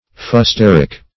Fusteric \Fus"ter*ic\, n. The coloring matter of fustet.